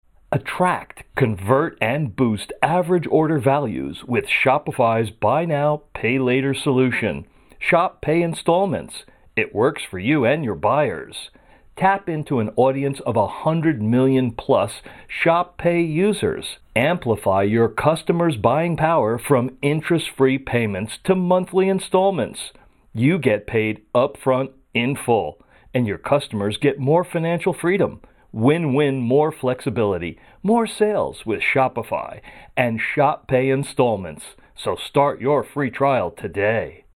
Male
Adult (30-50)
Warm, inviting, friendly, rich, authoritative, narration, clear, technical, commercial, soothing, calm, confident, upbeat, energetic, fun, punchy, trustworthy, credible, e-learning, audiobook, empathetic, Christian, announcer, radio, tv, corporate, educated, medical,
Corporate
Promo For Commercial Use
All our voice actors have professional broadcast quality recording studios.